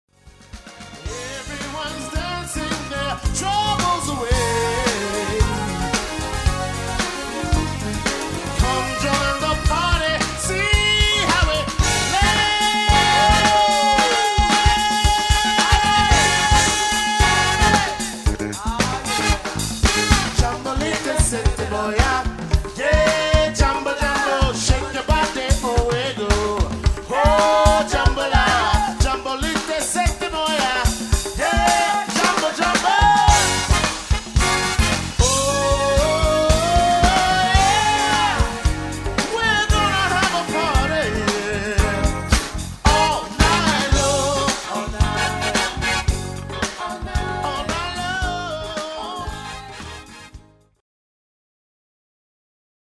Trombone
El.bass
Div. keyboards
Drums
Male* Buy it now!